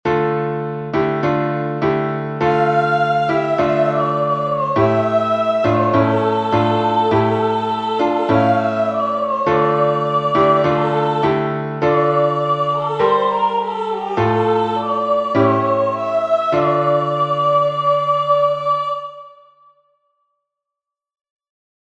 соло + аккомпанемент